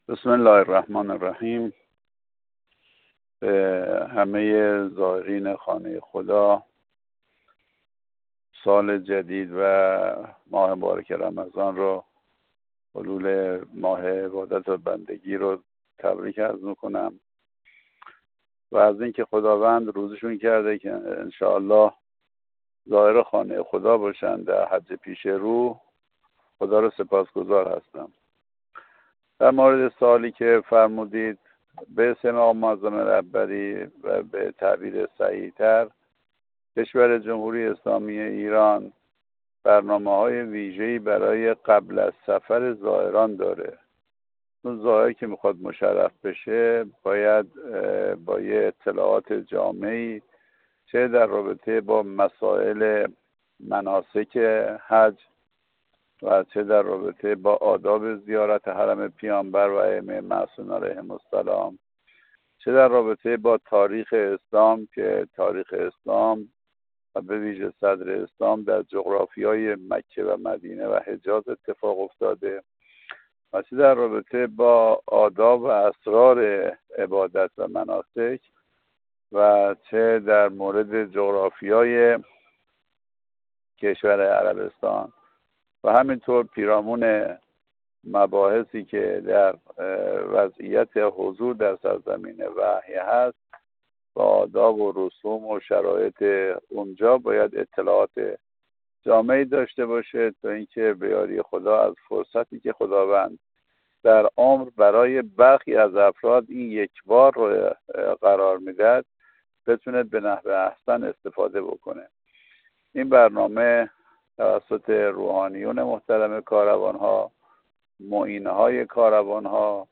حجت‌الاسلام نواب در گفت‌وگو با ایکنا مطرح کرد؛